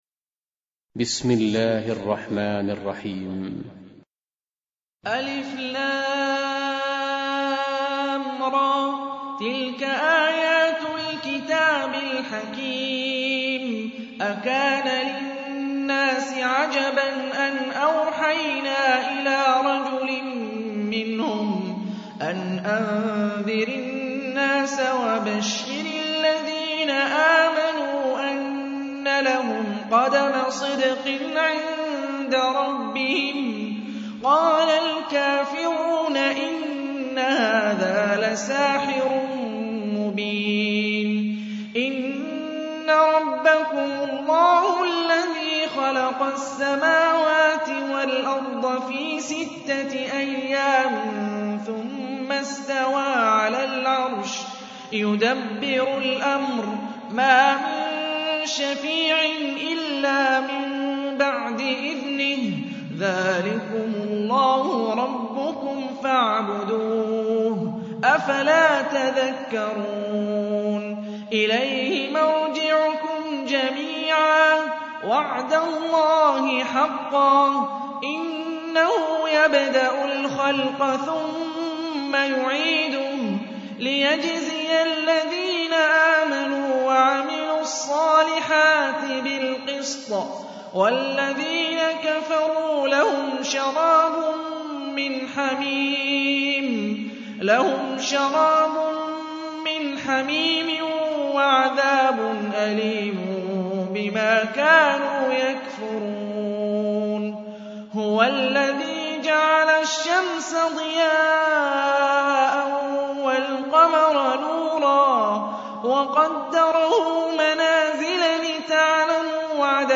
Қуръони карим тиловати, Қорилар. Суралар Qur’oni karim tilovati, Qorilar. Suralar